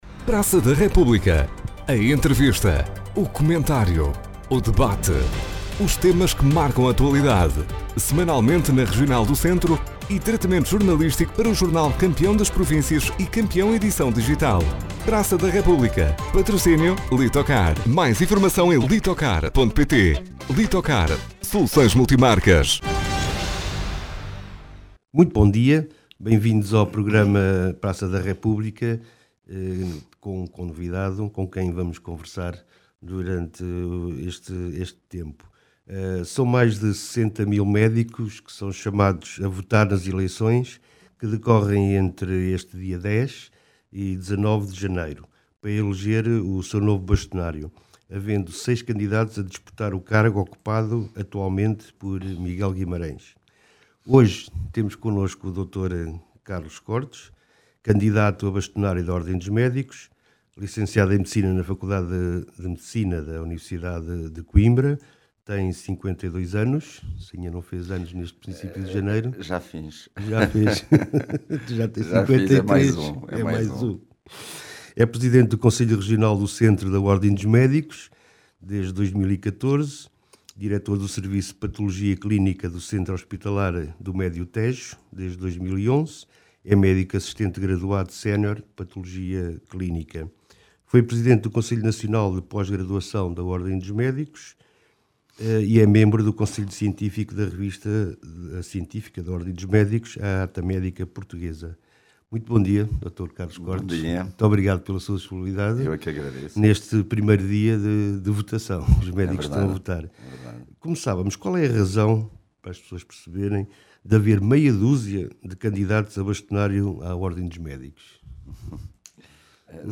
Praça da República – Entrevista